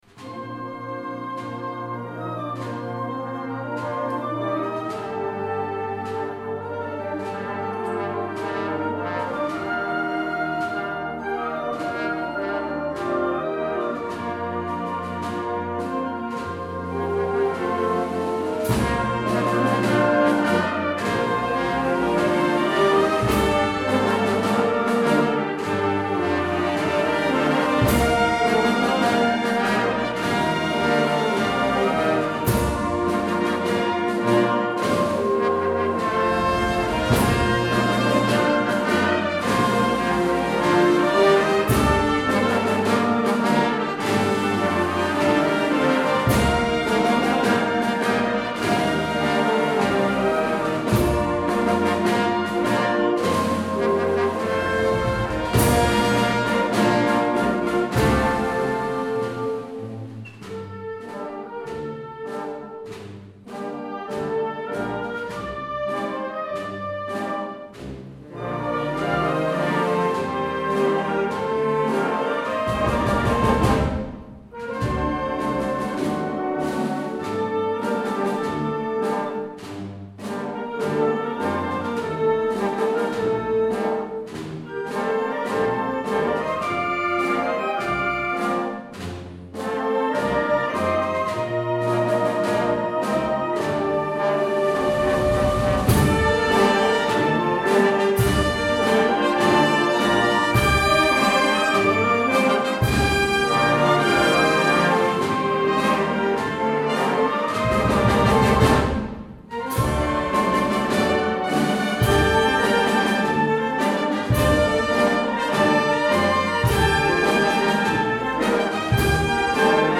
Concert de Setmana Santa - Auditori de Porreres.